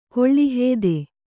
♪ koḷḷi hēde